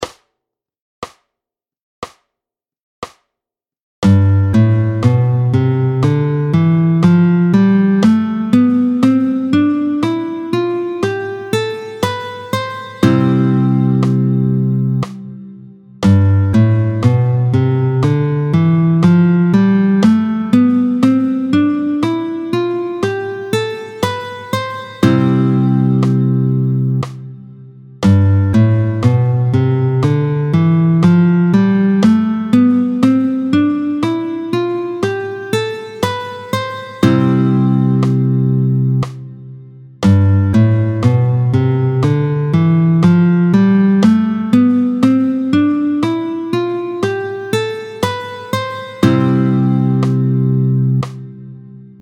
26-11 Doigté 5, Do majeur, tempo 60